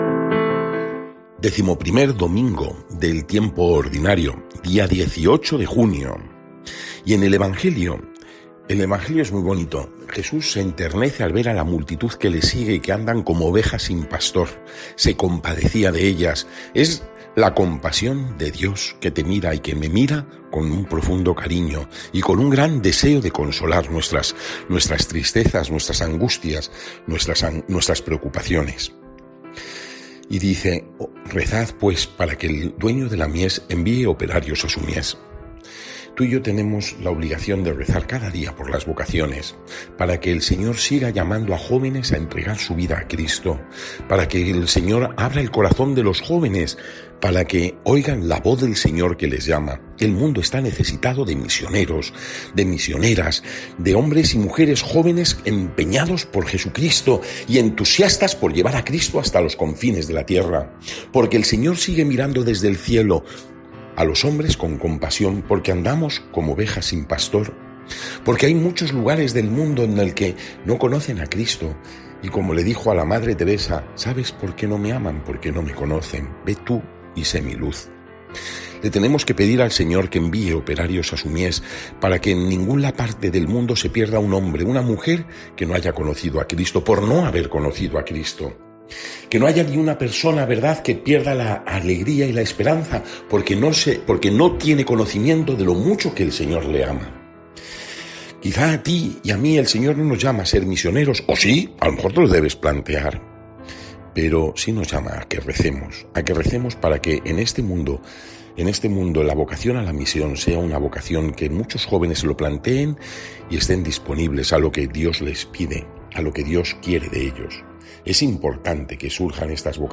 Lectura del santo evangelio según san Mateo 9, 36-10, 8